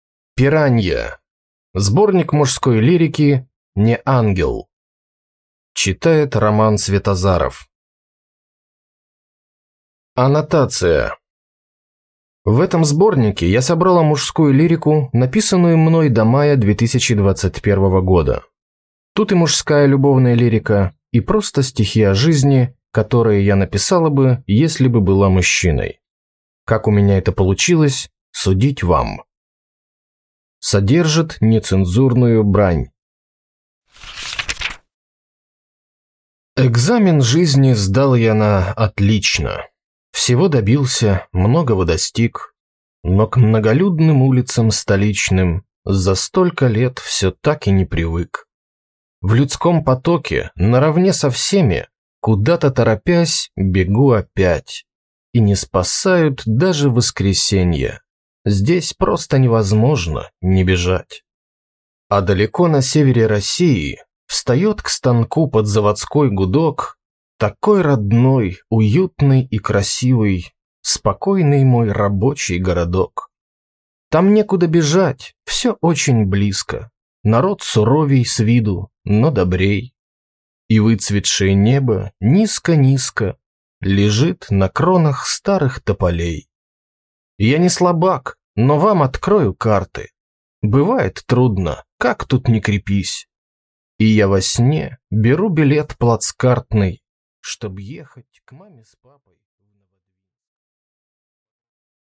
Аудиокнига Не Ангел | Библиотека аудиокниг